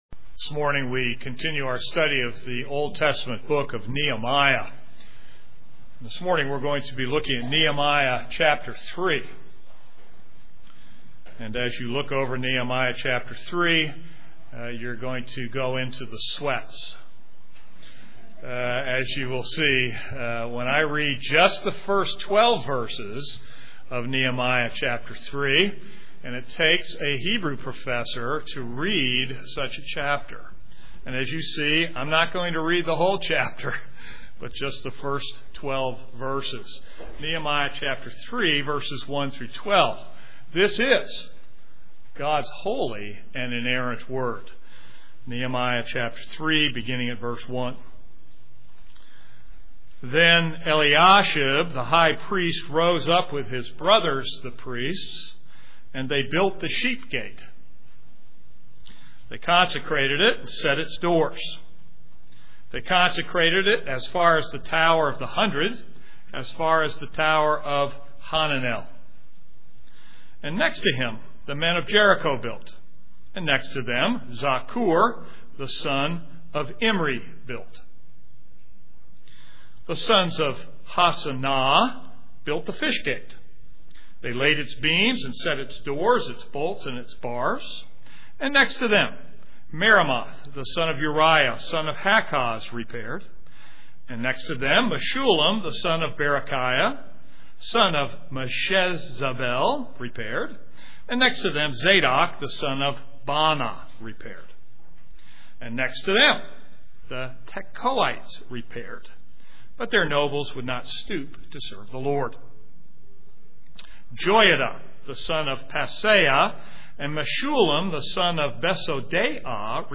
This is a sermon on Nehemiah 3.